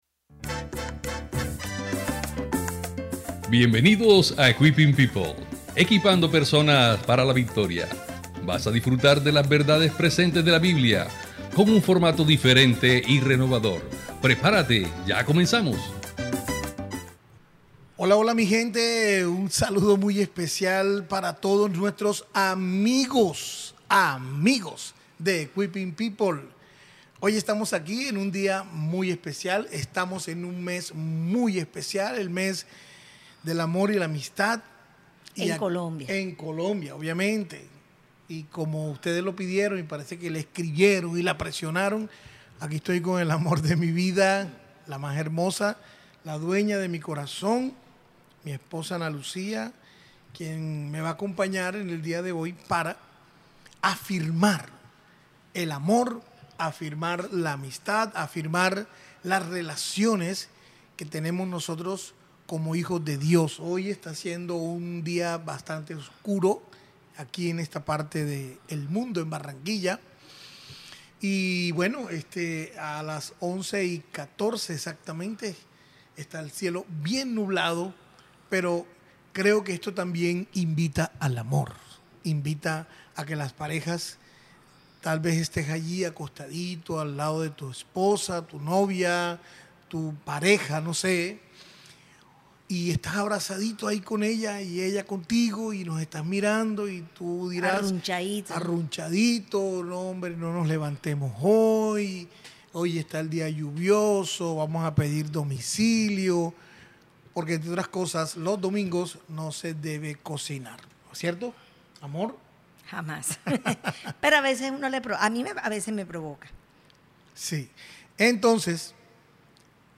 Una charla que tiene una informacion importante para tu vida.